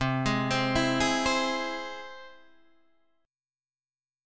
Listen to C+ strummed